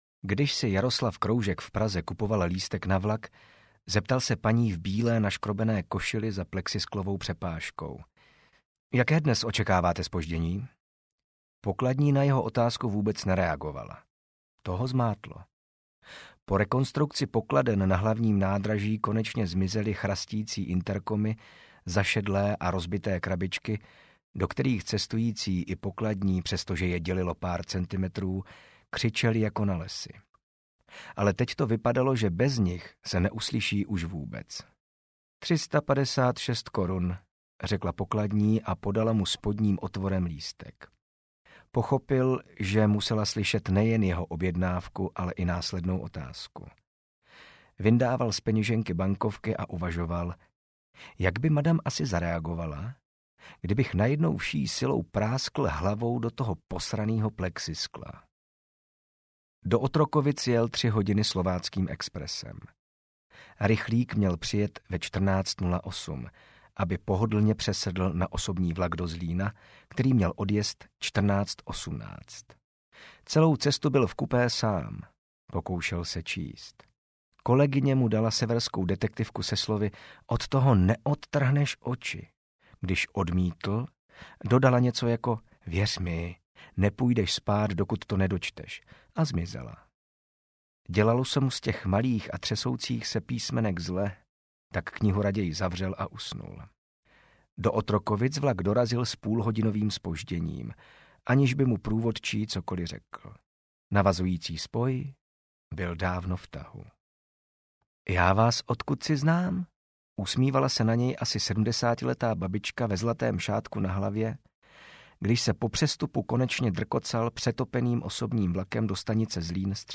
Vytržení audiokniha
Ukázka z knihy
vytrzeni-audiokniha